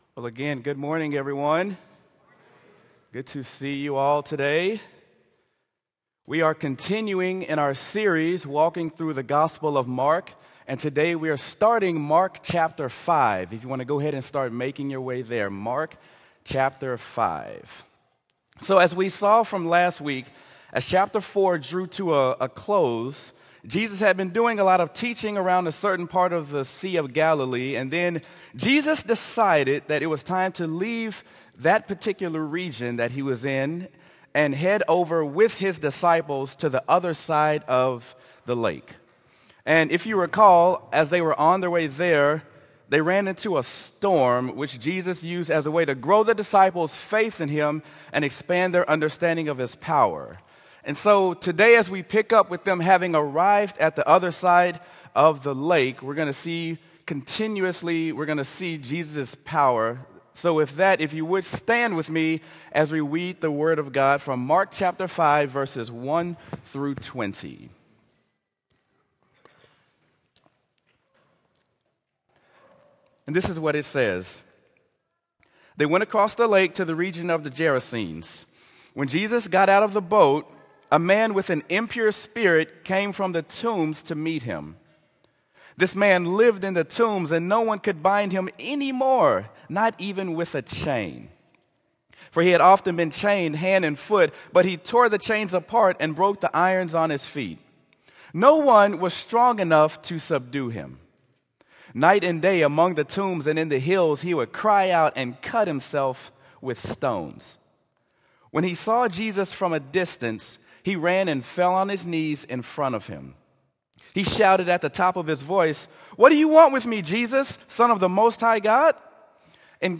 Sermon: Mark: Demons and Pigs: Jesus’ Supernatural Power
sermon-mark-demons-and-pigs-jesus-supernatural-power.m4a